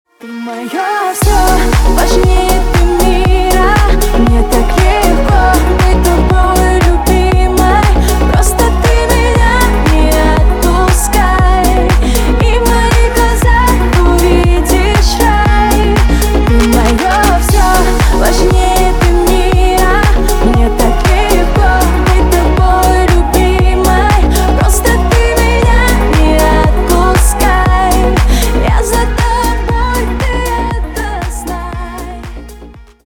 бесплатный рингтон в виде самого яркого фрагмента из песни
Поп Музыка